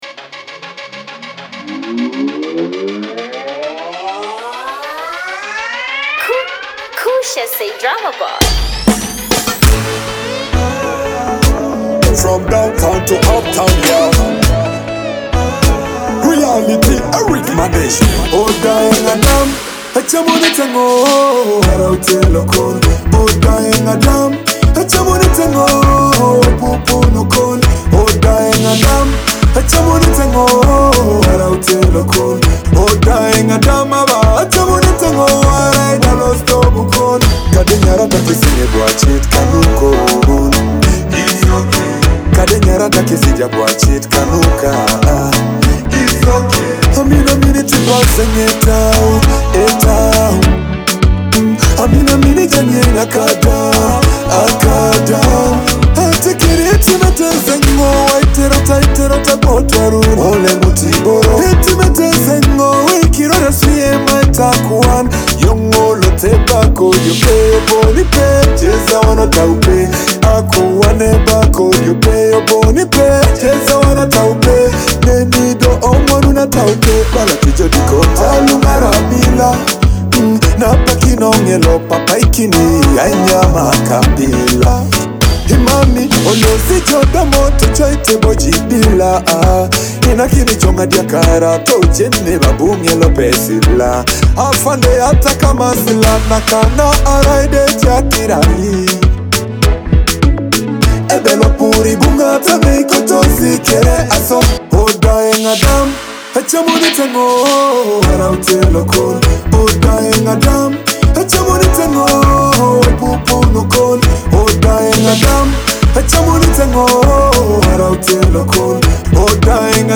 or simply enjoying the energy of modern Teso Dancehall.